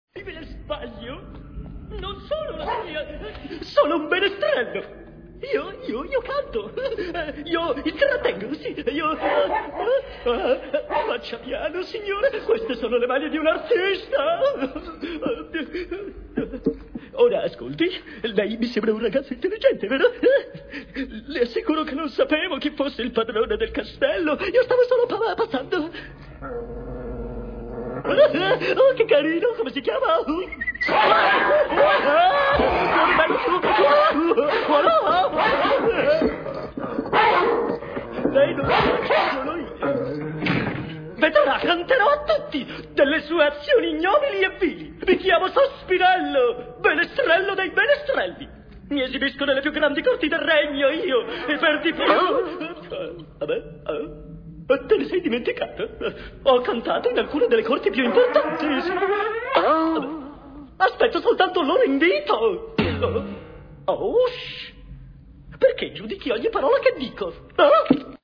nel film d'animazione "Taron e la pentola magica", in cui doppia Sospirello.